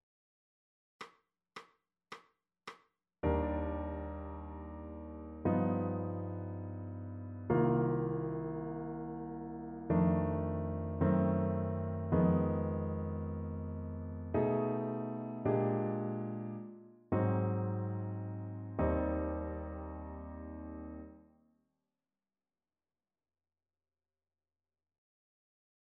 Jazz i música moderna
A2-dictat-harmonic-jazz-moderna-audio-24-06.mp3